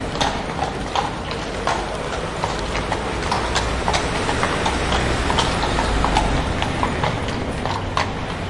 马车和交通噪音
描述：pferde kutsche verkehr stadt
Tag: 交通 运输 城市 现场录音 街道 城市 城区 verkehr kutsche pferde